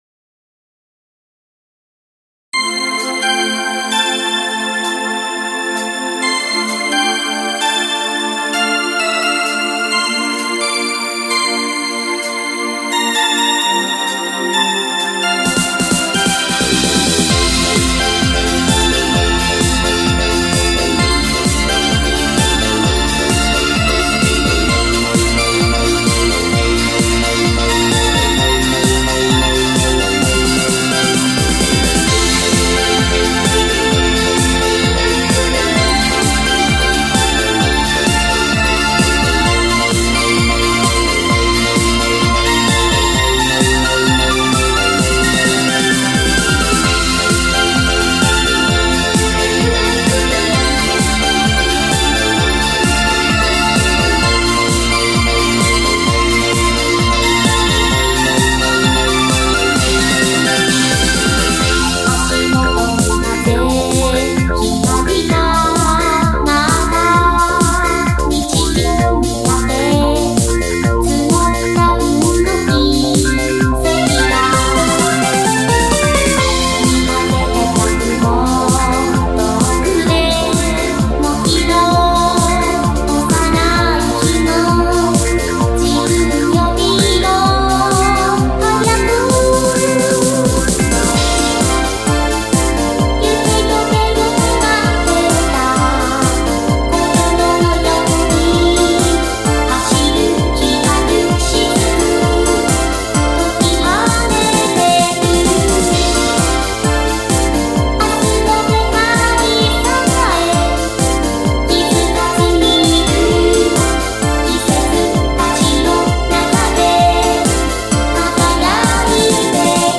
MP3版ボーカル付
今回はテンポが速い曲なので、機械的な部分が多く 出ていたので、かなりパラメーターをいじっています。
オケのMIDIの方はそのまま使用しているので内容的には変わっていません。
今回は、ボーカルにディエッサーを使用してみました。多少は不快なブレス音が軽減されたと思います。